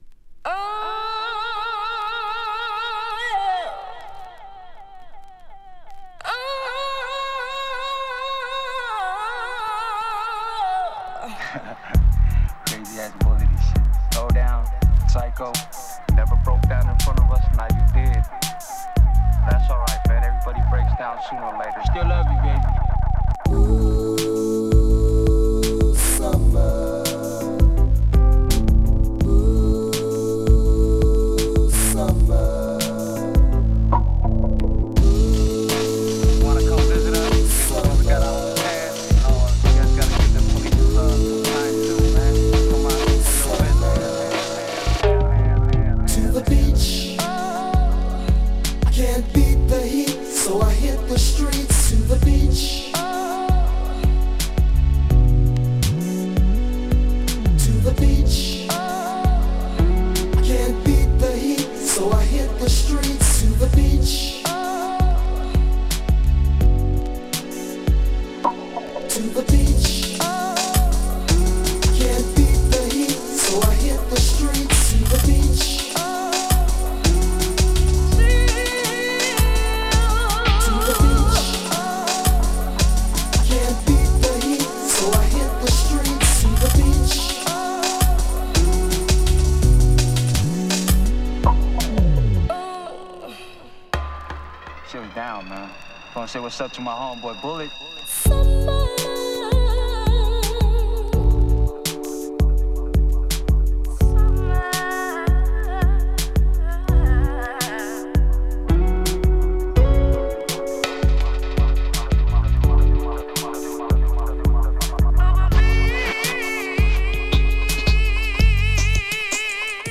時折入る波の音もこれまた最高!!